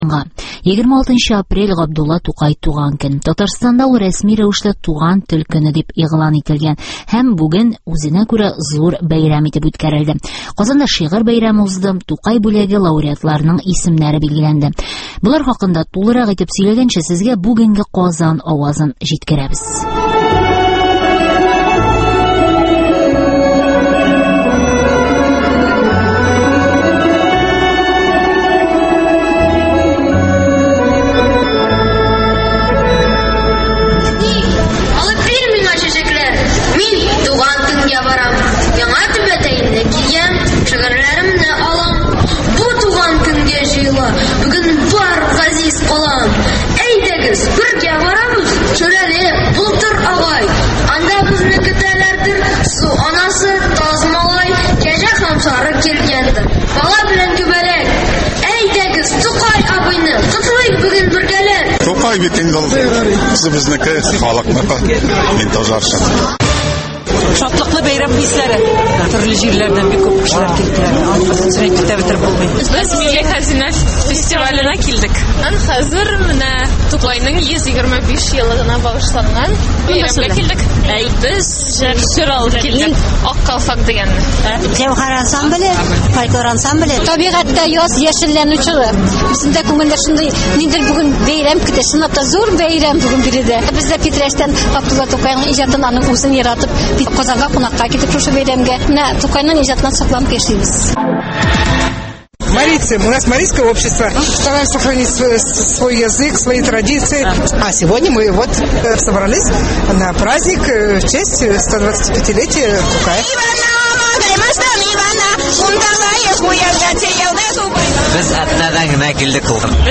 Туган тел көненнән репортаж (1)